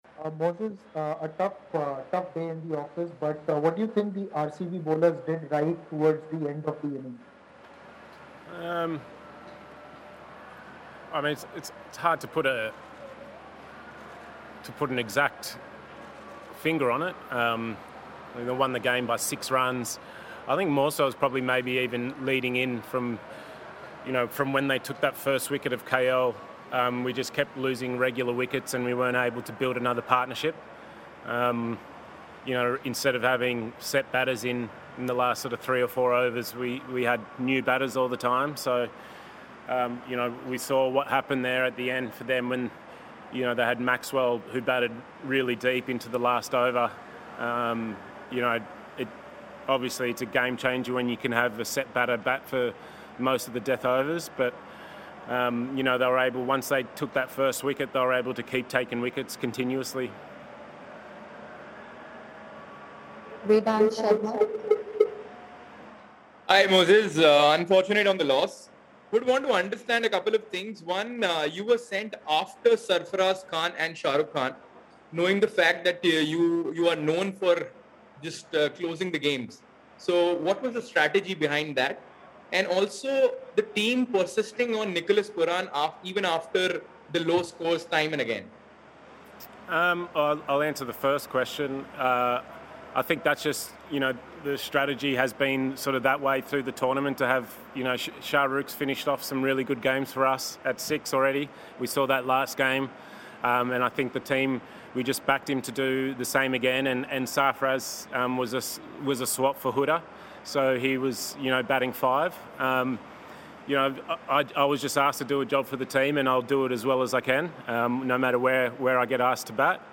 Moises Henriques of Punjab Kings addressed the media after the game